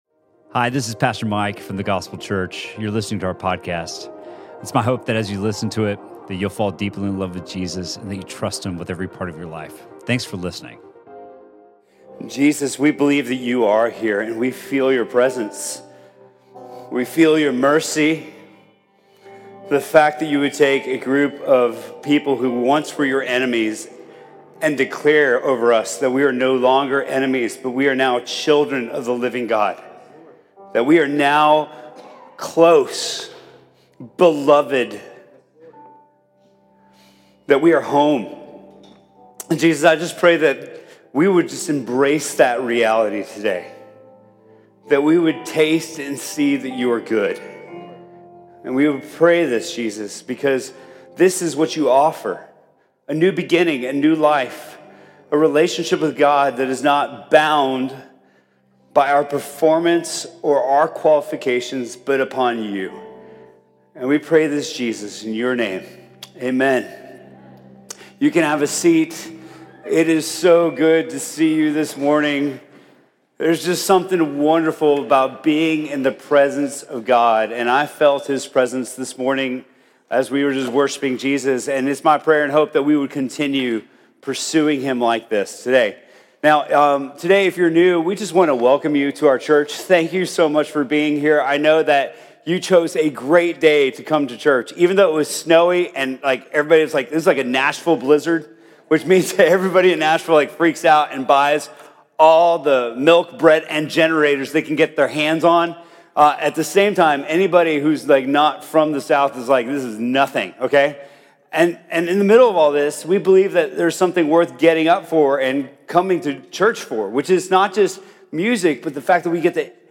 Sermon from The Gospel Church on January 20th, 2019.